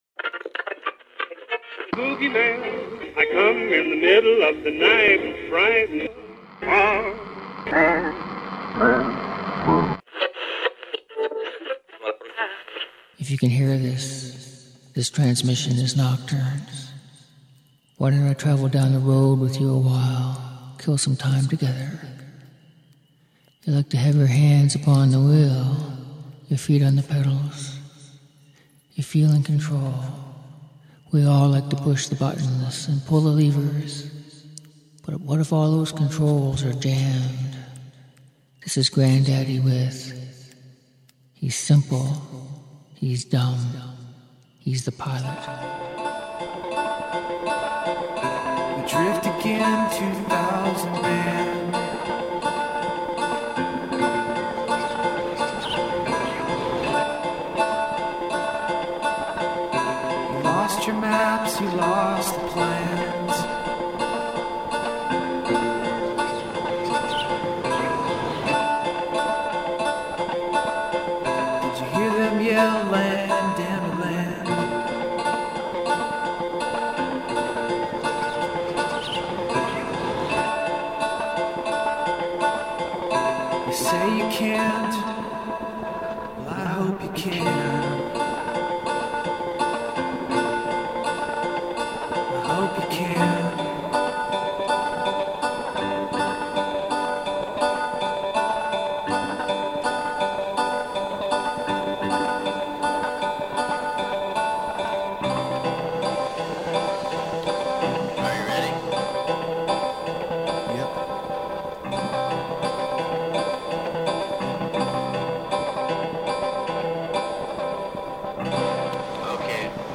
Music For Nighttime Listening